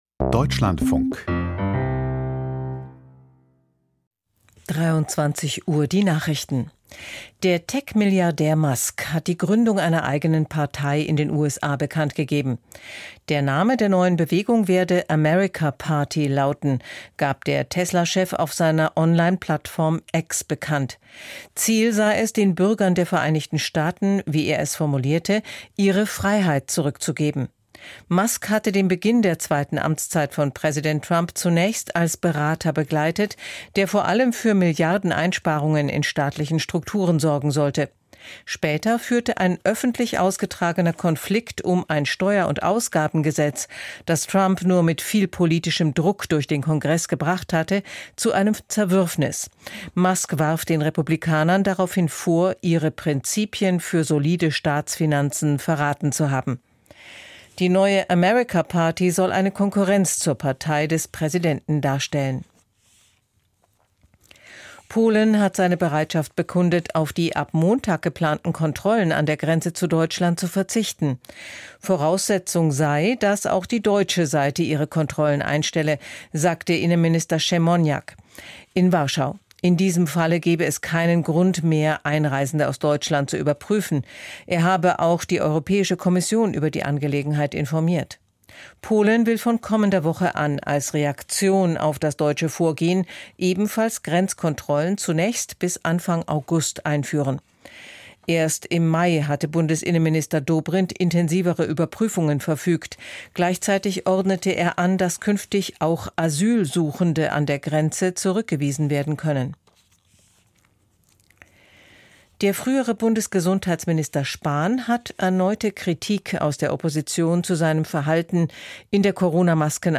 Die Nachrichten vom 05.07.2025, 23:00 Uhr